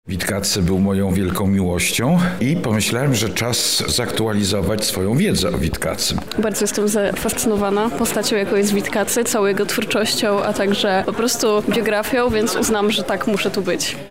„Portret Witkacego”, uczestnicy wydarzenia